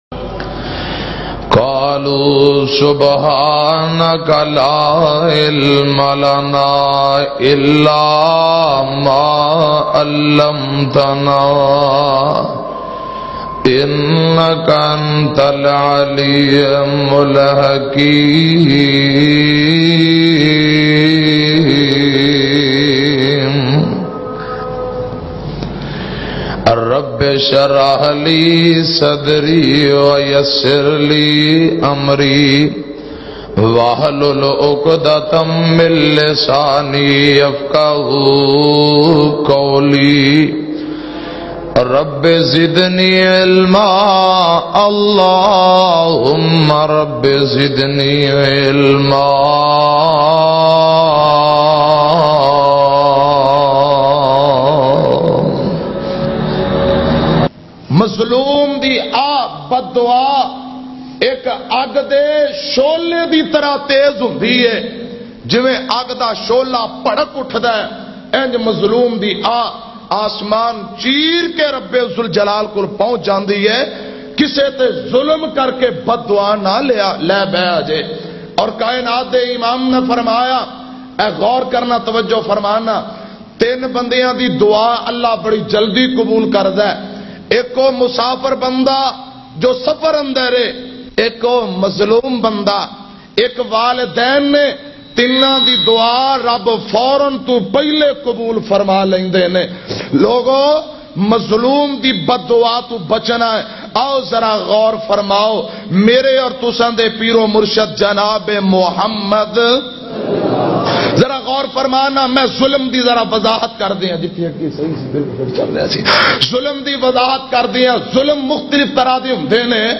Mazloom ki pakar bayan mp3